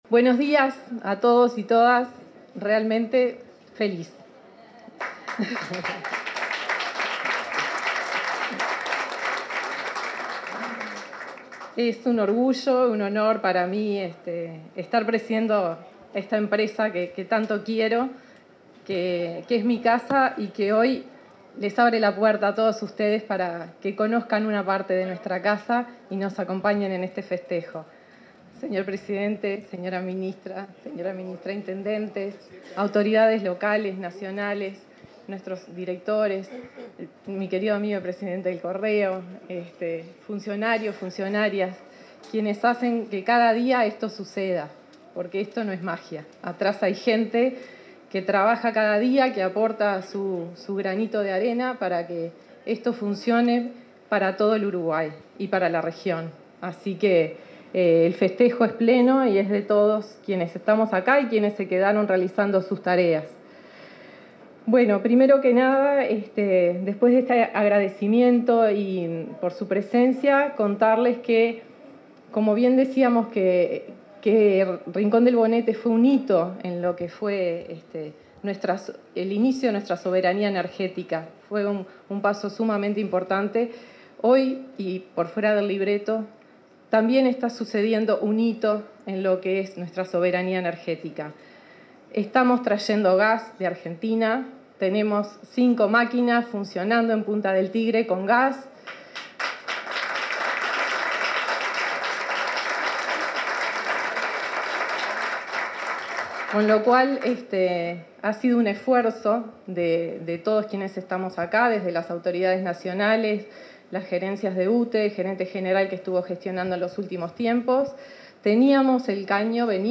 Palabras de la presidenta de UTE, Andrea Cabrera 04/12/2025 Compartir Facebook X Copiar enlace WhatsApp LinkedIn En el 80.° aniversario de la central hidroeléctrica Rincón del Bonete, ubicada en Paso de los Toros, Tacuarembó, hizo uso de la palabra la presidenta de UTE, Andrea Cabrera.